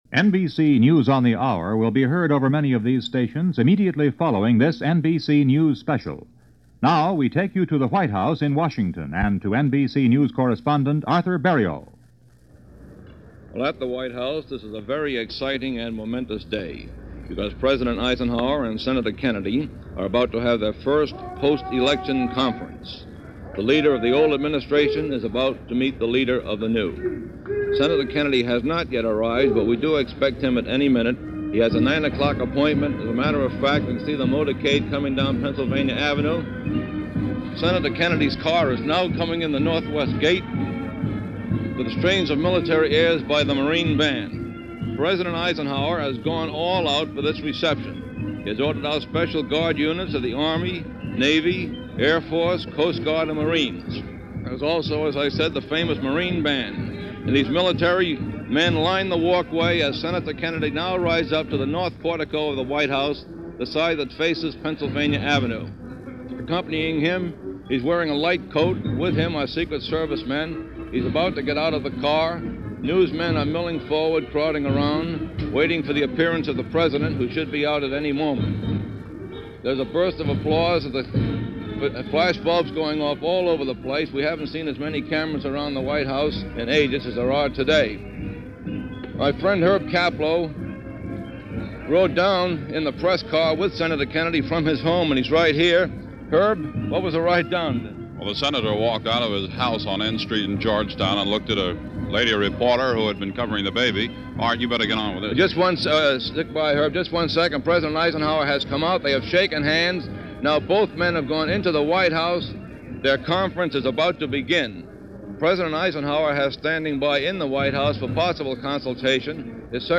Kennedy at The White House, and so much more for this December 6, 1960, as reported over NBC Radio’s News On The Hour.